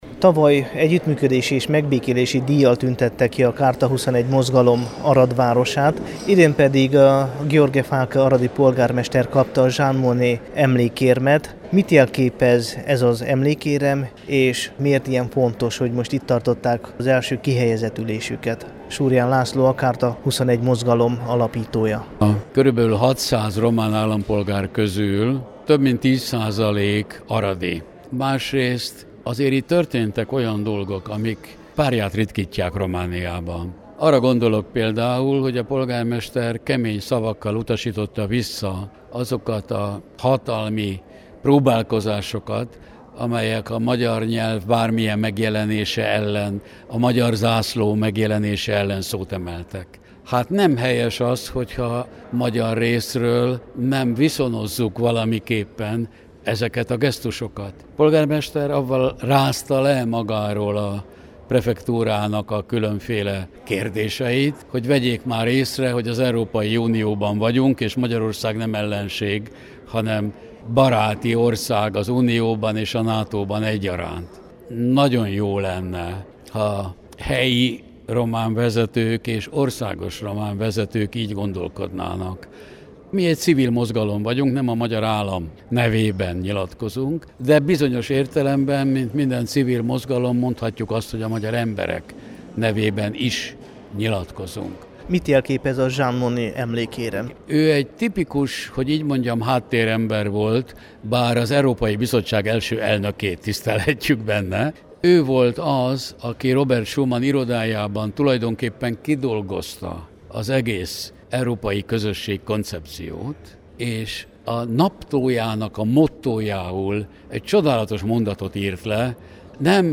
surjan_laszlo-interju.mp3